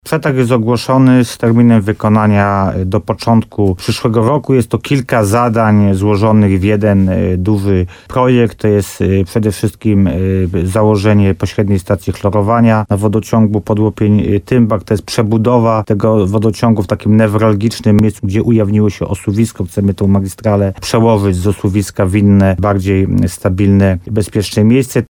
Wójt Paweł Ptaszek zapewniał w programie Słowo za słowo na antenie RDN Nowy Sącz, że gmina ma przygotowany plan inwestycji, które mają poprawić sytuacje.